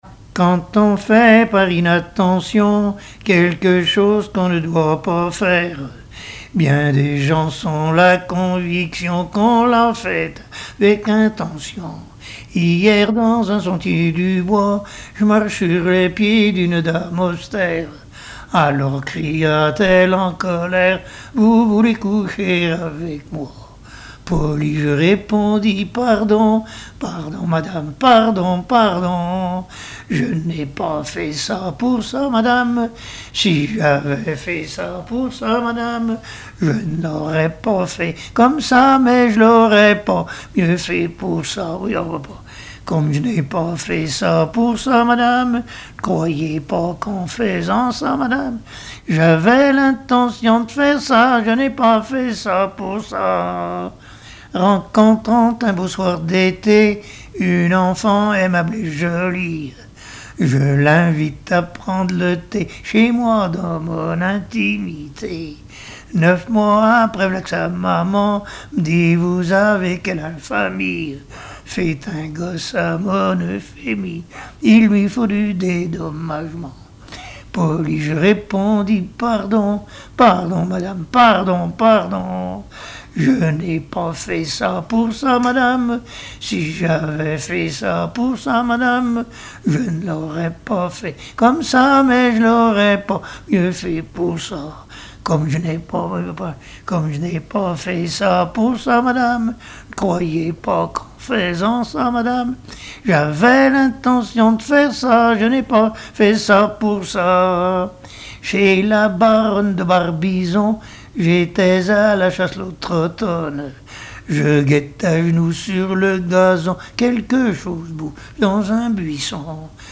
Genre strophique
Histoires drôles et chansons traditionnelles
Pièce musicale inédite